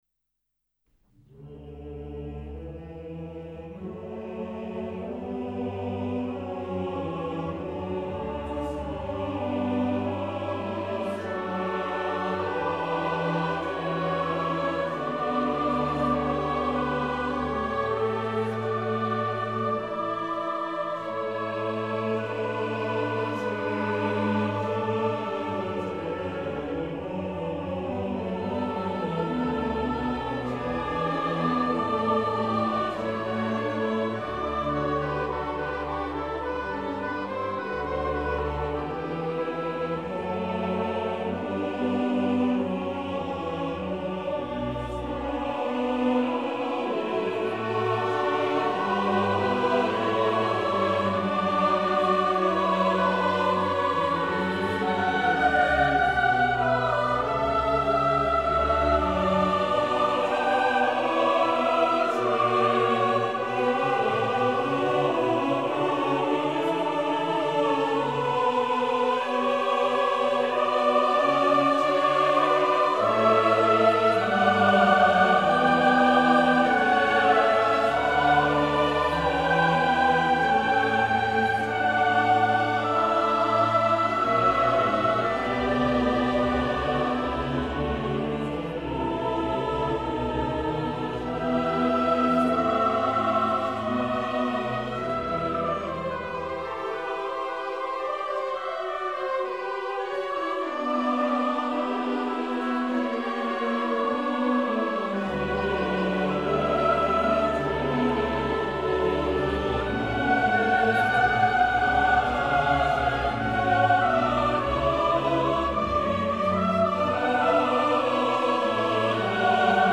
Dona nobis pacem- Choeur et piano
SATB et piano - Johann Sebastian Bach
Partie choeur et réduction piano, extrait de la Messe en Si mineur, BWV 232 de Jean-Sébastien BACH (1685-1750) pour choeur SATB à quatre voix mixtes et piano